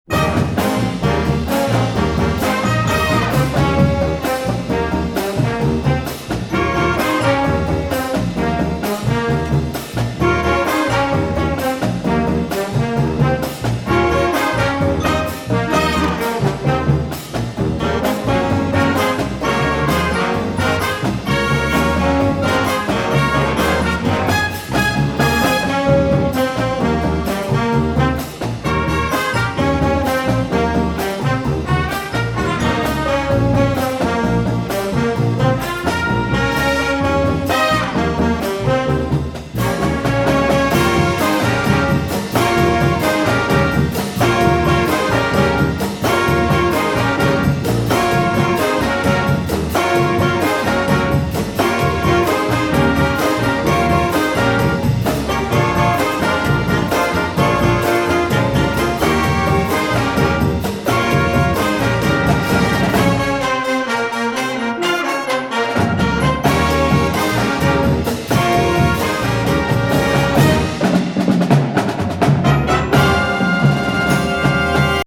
Instrumental Marching Band 70's, 80's & 90's